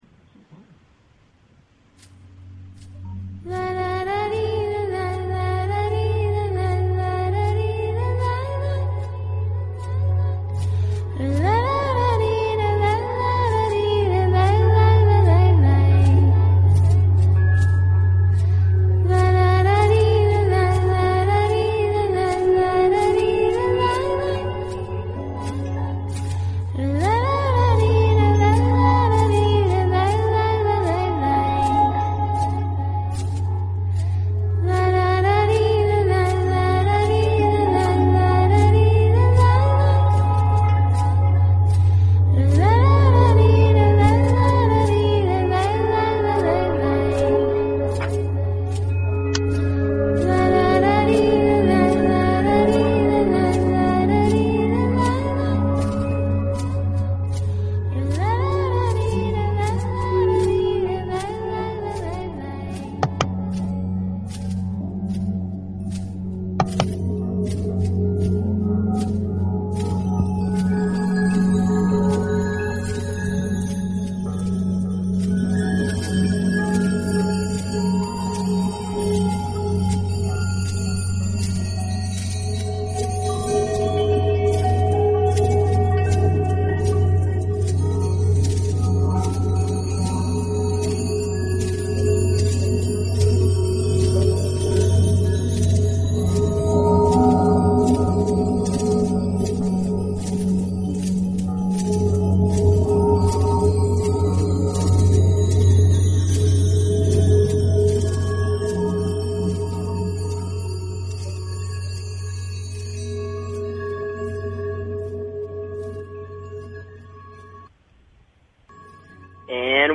Talk Show Episode, Audio Podcast, Evolver_Planet_Radio and Courtesy of BBS Radio on , show guests , about , categorized as